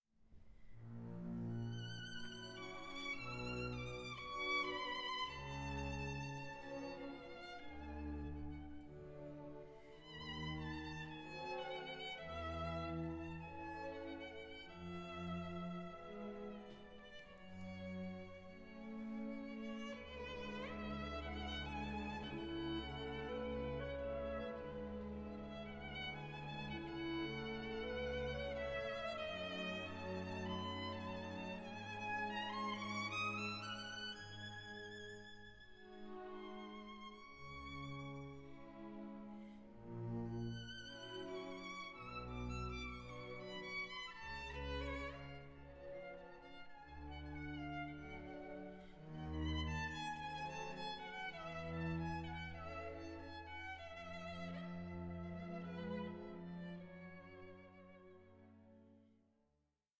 ballet in four acts